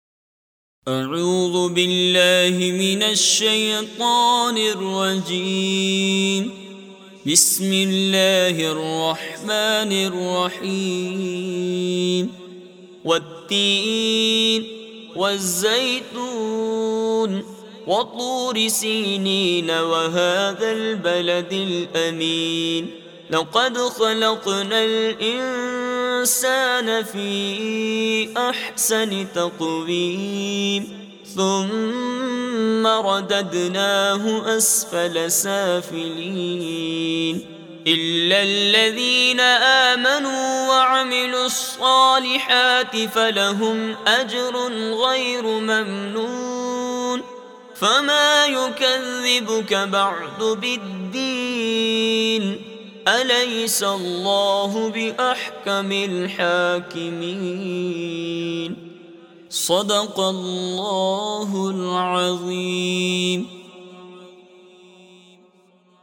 From this page, you can read Surah At Tin online listen to its mp3 audio, download recitations, and download PDF to read it offline with Urdu and English translations for better understanding.